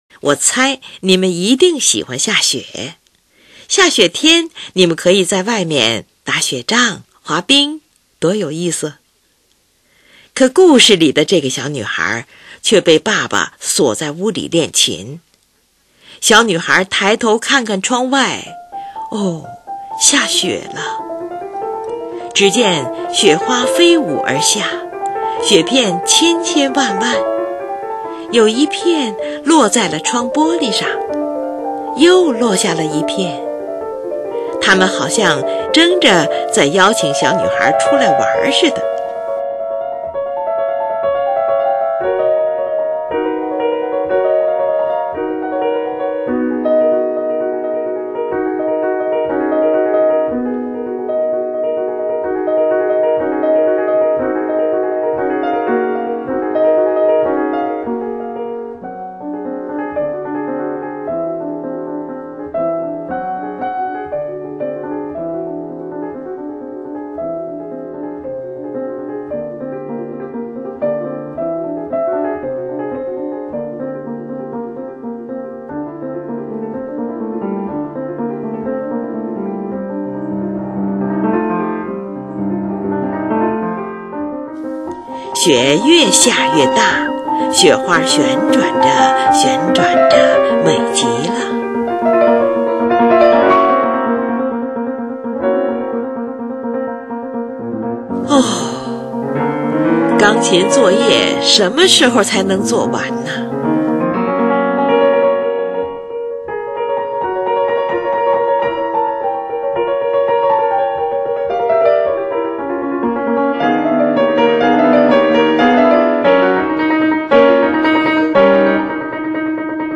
作者运用大量的五度与八度音程，制造出干净、透亮的音色，好像是在赞美雪的洁白与纯洁。
快速流出的伴奏中的十六分音符将旋律的空档填满，像是雪越下越急，越下越大了。
伴奏中两个音的来回敲击描绘着雪花在旋转的样子。在大雪纷飞中，音乐消失了。